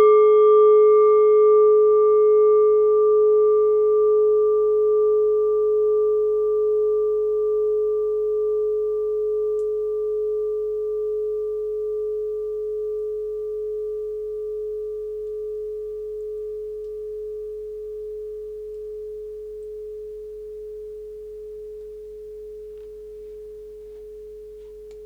Klangschale Nepal Nr.32
(Ermittelt mit dem Filzklöppel oder Gummikernschlegel)
klangschale-nepal-32.wav